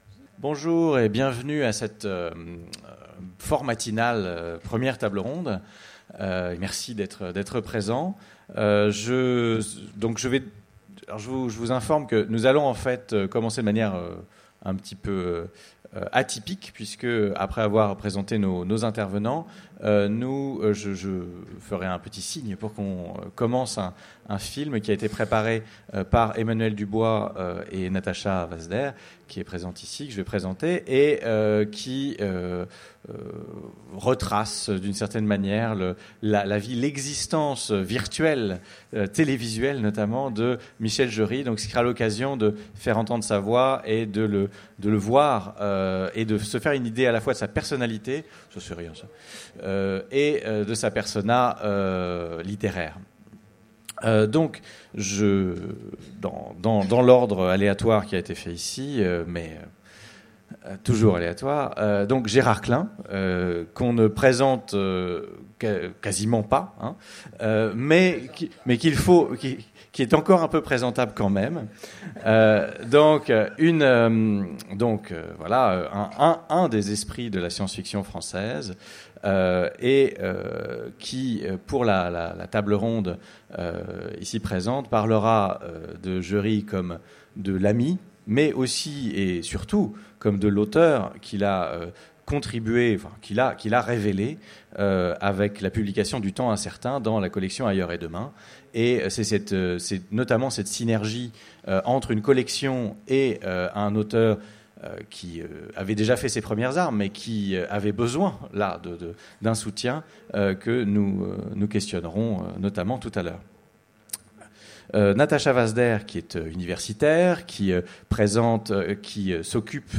Utopiales 2015 : Conférence L’homme qui cultivait le futur
Utopiales2015MichelJeury.mp3